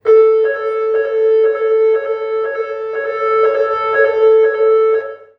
Evacuation alarm #2
Sound of the evacuation siren in a concert hall, triggered by an electrical issue. Each such sequence is normally interspersed with a message in French and English, urging people to leave the building, but I have removed it.
UCS Category: Alarms / Electronic (ALRMElec)
Channels: Monophonic
Conditions: Indoor